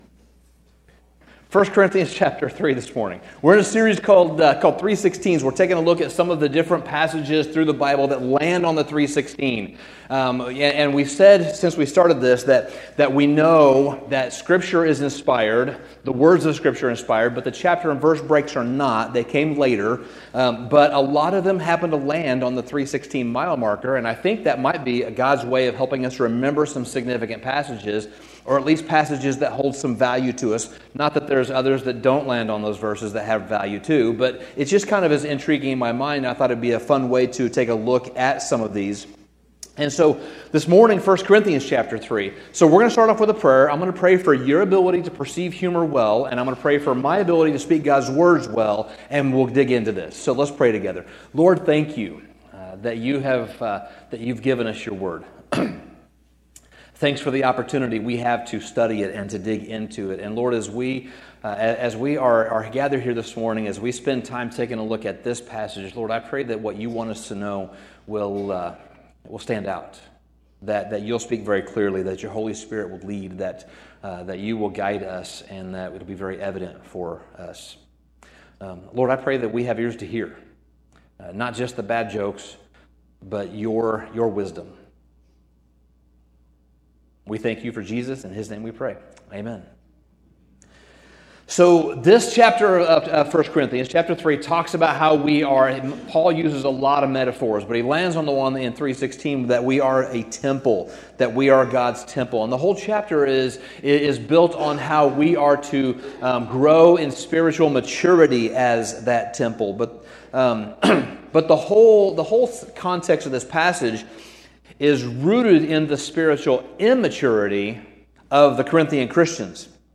Sermon Summary The church in Corinth was a church that Paul planted, and a church that Paul loved.